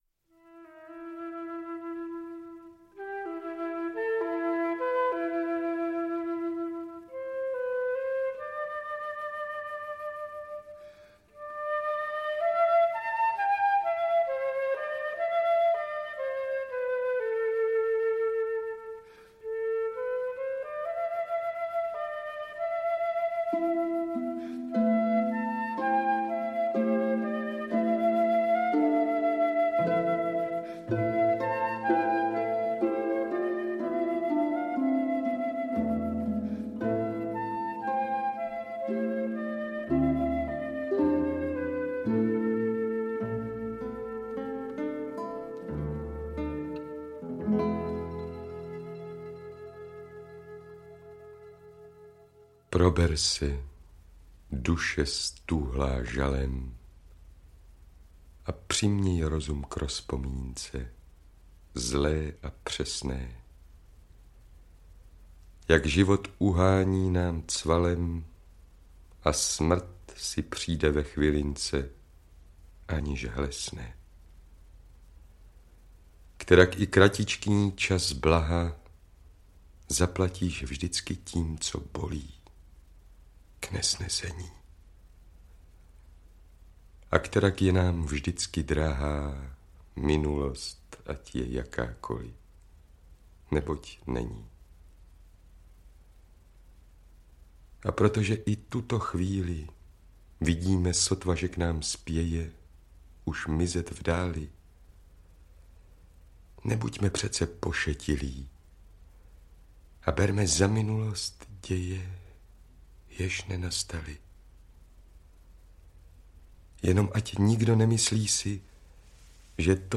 • Čte: Jiří Adamíra